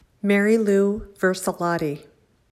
I’m a native speaker of the Western Pennsylvania Dialect of American English. I have added features of other Midlands dialects to my idiolect.